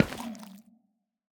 Minecraft Version Minecraft Version snapshot Latest Release | Latest Snapshot snapshot / assets / minecraft / sounds / block / sculk_catalyst / step5.ogg Compare With Compare With Latest Release | Latest Snapshot
step5.ogg